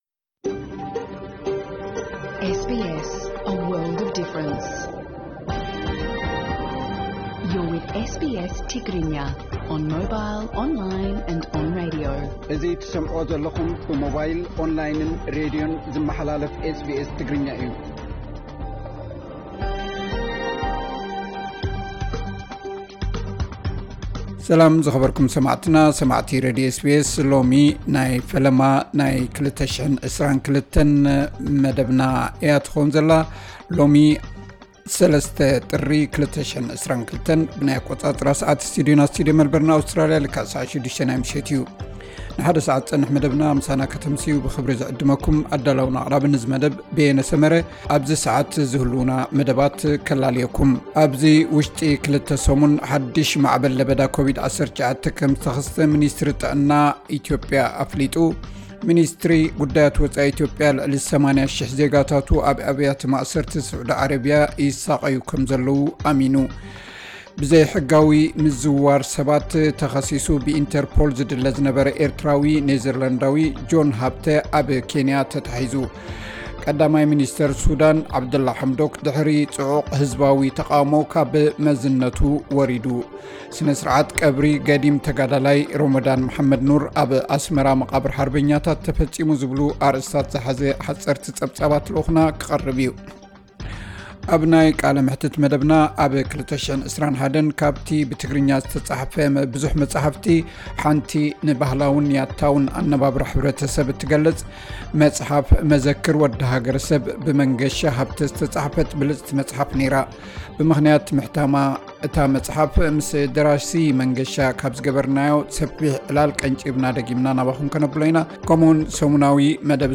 ዕለታዊ ዜና SBS ትግርኛ (03 ጥሪ 2022)